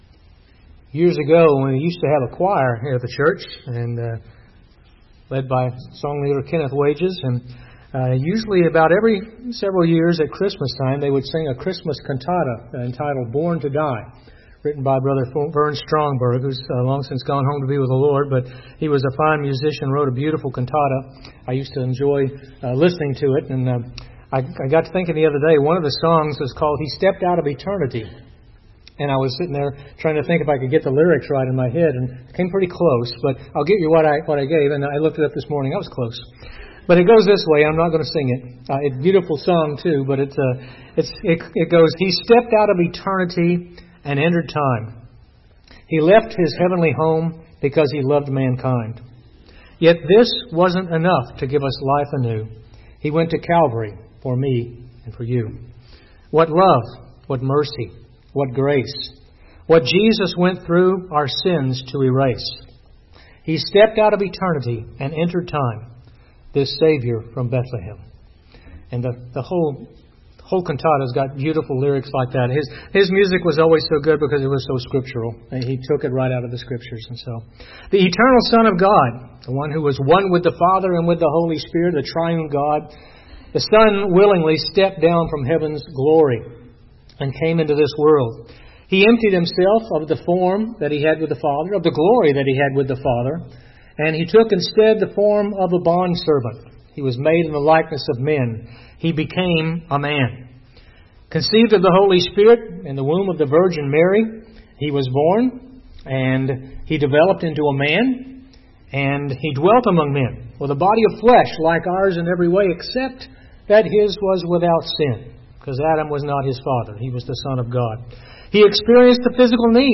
Listen to Bible sermons from our Sunday Morning Worship Services (all Bible Study Sermons are in MP3 format).
The preaching is expository in approach (examining Bible passages in context), even when addressing topical areas (i.e. salvation, Christian walk, faith, etc.).